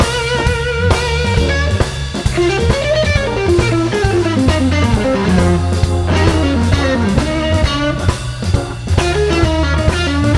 Diminished Scales and The Cool Riff